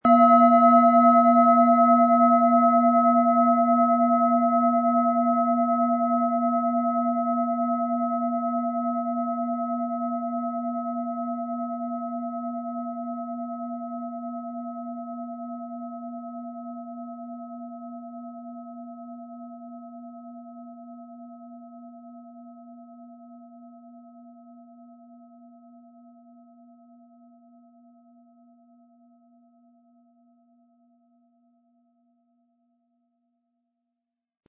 Jede Schale klingt klar, warm und kraftvoll – getragen von ihrer eigenen Geschichte.
Tiefster Ton: Mond
Mittlerer Ton: Chiron
Unter dem Artikel-Bild finden Sie den Original-Klang dieser Schale im Audio-Player - Jetzt reinhören.
Der Schlegel lässt die Schale harmonisch und angenehm tönen.
MaterialBronze